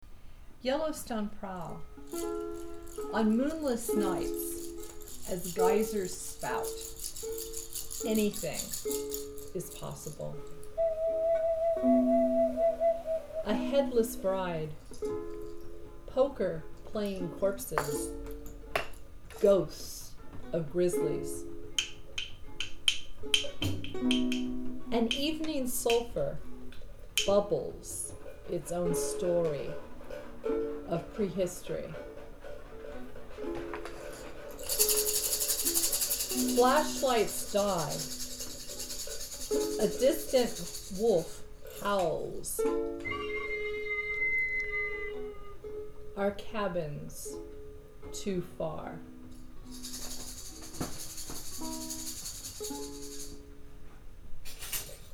ukulele
spring drum
tambourine
pennywhistle and other percussion instruments